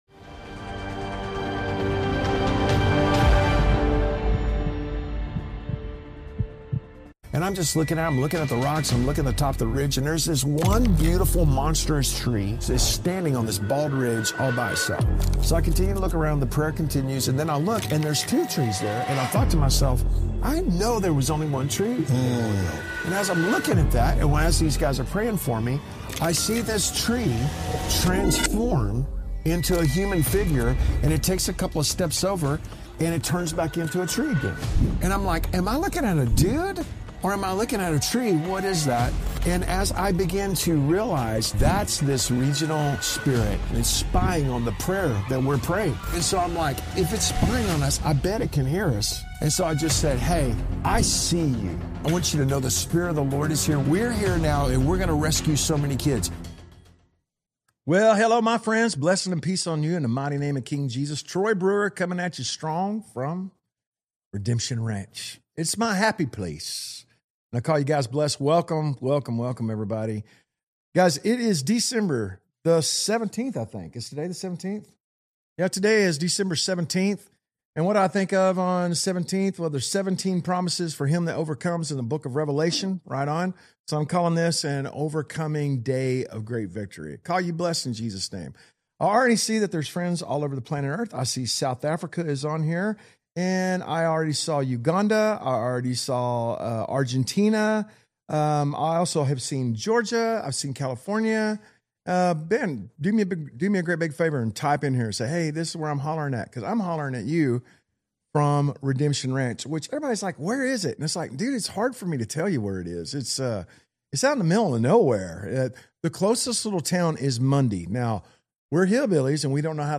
LIVE from Redemption Ranch (Day 2) | The Pulse - Ep 102